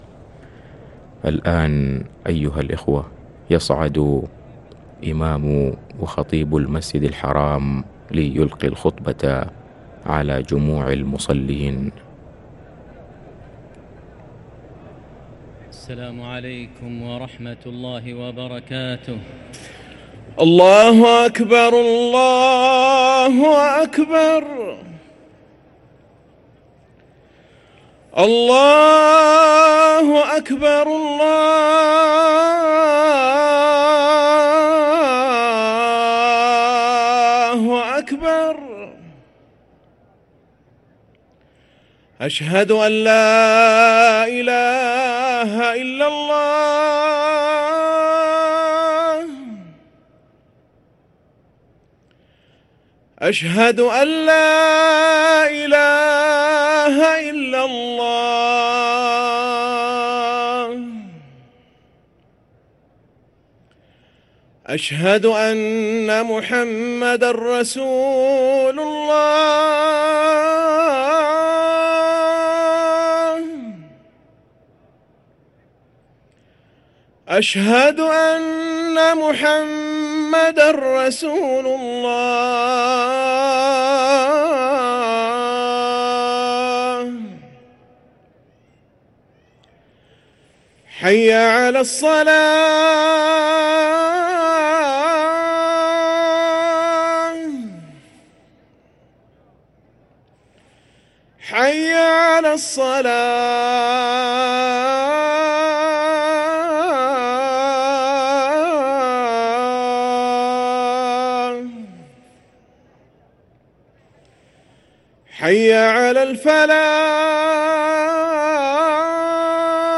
أذان الجمعة الثاني
ركن الأذان